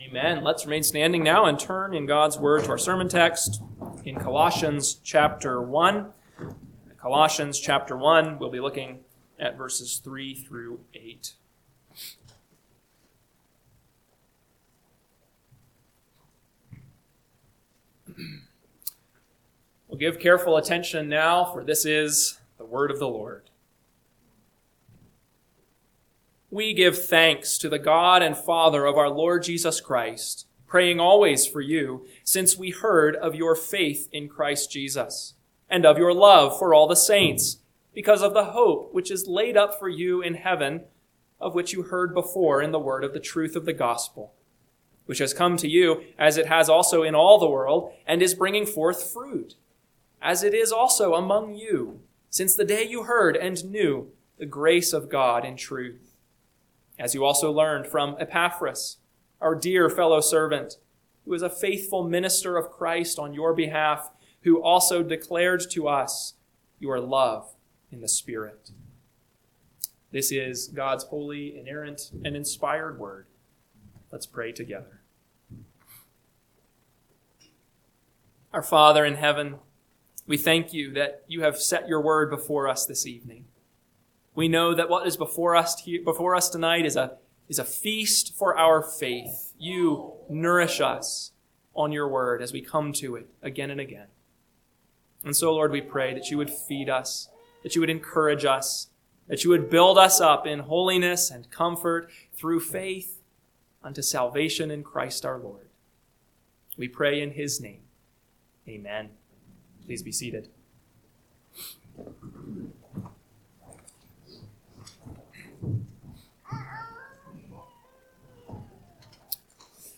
PM Sermon – 12/21/2025 – Colossians 1:3-8 – Northwoods Sermons